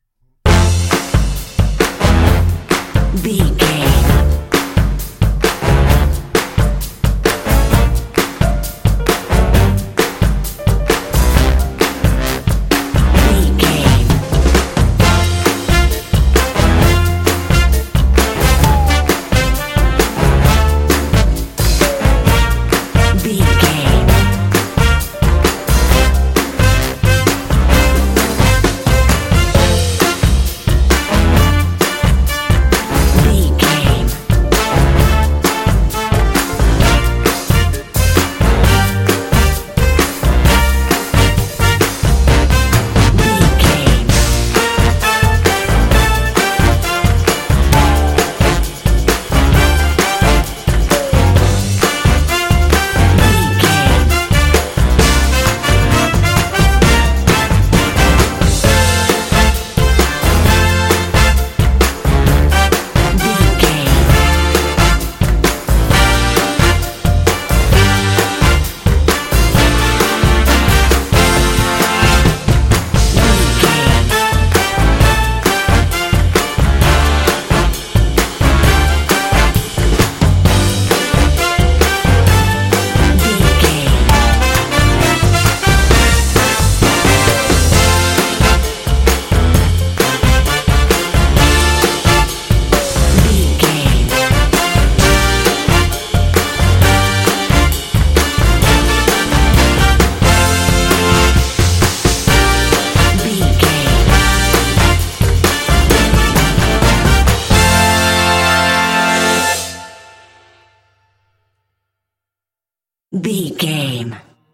Uplifting
Ionian/Major
happy
bouncy
groovy
drums
brass
electric guitar
bass guitar
strings
rock and roll